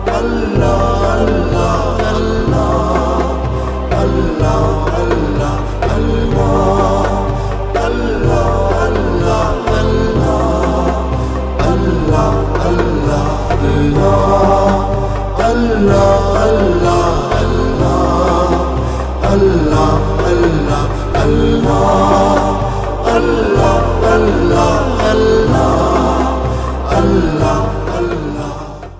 نغمة دينية هادئة ورائعة جدا تبعث على الراحة النفسية